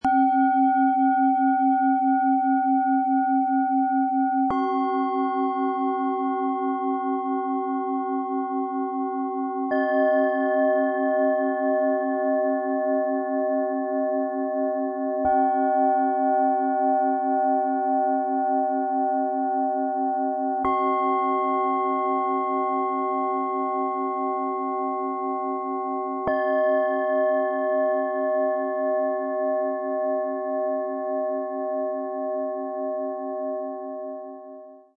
Im gemeinsamen Klang entfaltet sich eine kraftvolle Balance.
Im Sound-Player - Jetzt reinhören hören Sie den Originalton genau dieser drei Schalen. Die feine Aufnahme zeigt, wie harmonisch das Set für Klangmeditation wirkt – ruhig, ausgewogen und klar.
Tiefster Ton: Biorhythmus Körper
Mittlerer Ton: Biorhythmus Geist
Höchster Ton: Eros
MaterialBronze